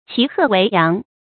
騎鶴維揚 注音： ㄑㄧˊ ㄏㄜˋ ㄨㄟˊ ㄧㄤˊ 讀音讀法： 意思解釋： 同「騎鶴上揚州」。